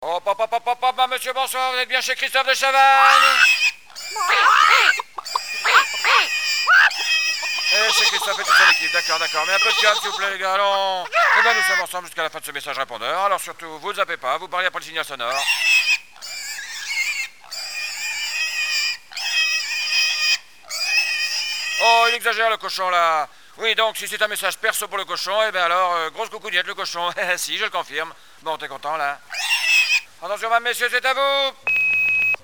Messages d'imitations 1: